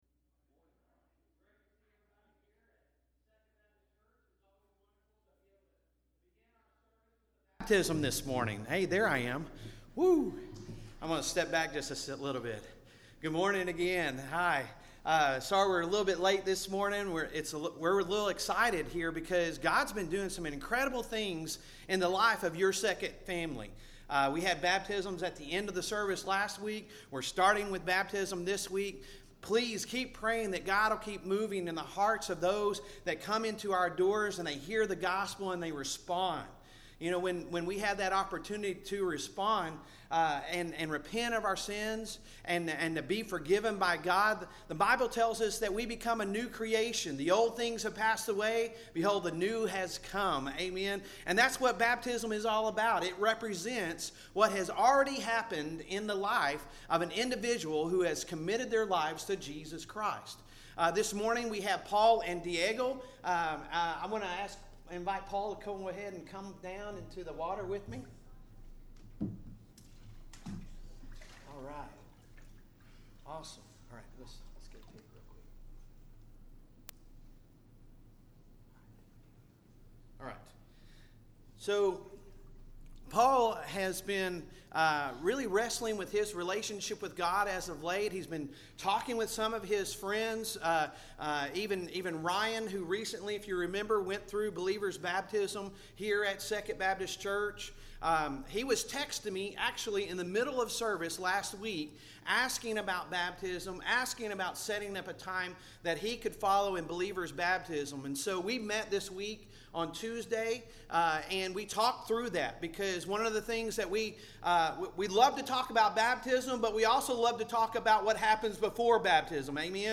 Sunday Sermon October 1,2023